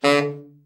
TENOR SN   7.wav